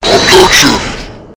objection.wav